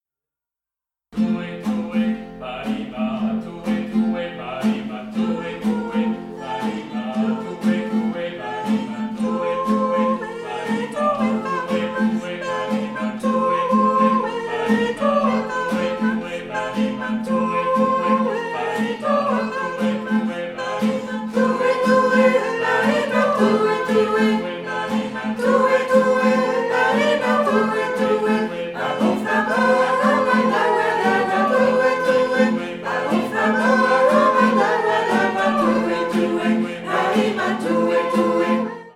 Démarche d’apprentissage et extrait sonore de « Tue tue », folk song du Ghana – Cycle 3.